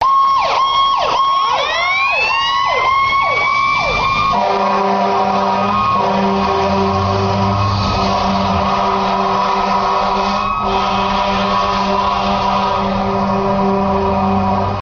دانلود آهنگ آژیر 5 از افکت صوتی حمل و نقل
جلوه های صوتی
دانلود صدای آژیر 5 از ساعد نیوز با لینک مستقیم و کیفیت بالا